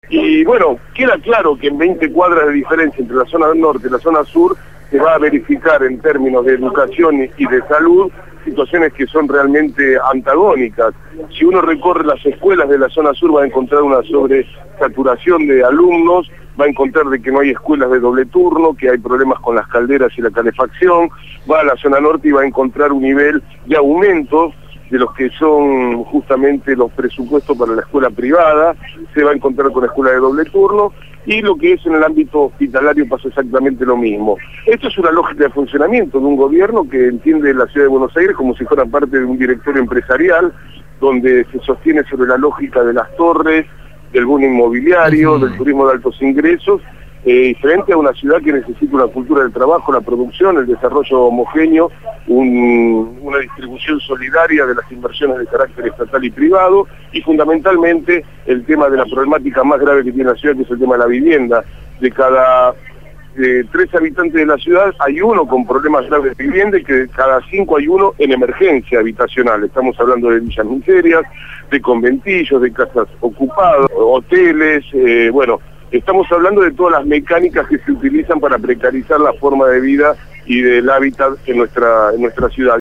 Presente en el acto, Fabio Basteiro, legislador por Proyecto Sur habló sobre la contaminación que provoca el cementerio de autos.